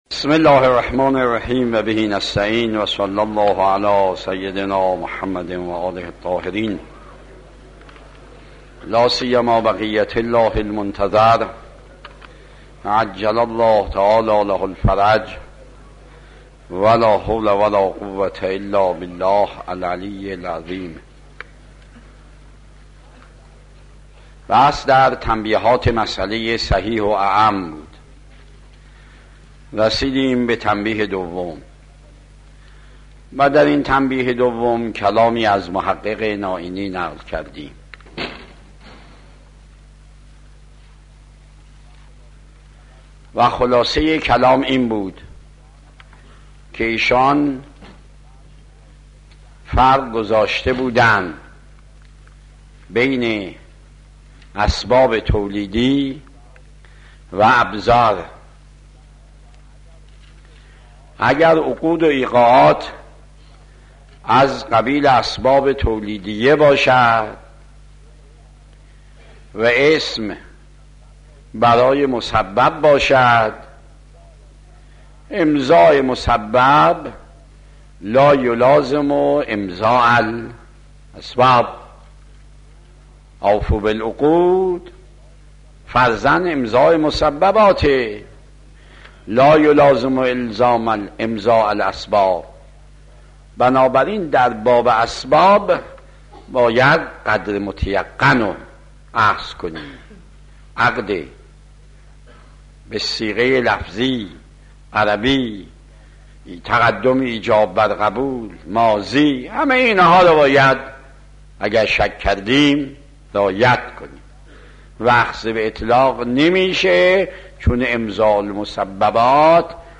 آيت الله مکارم شيرازي - خارج اصول | مرجع دانلود دروس صوتی حوزه علمیه دفتر تبلیغات اسلامی قم- بیان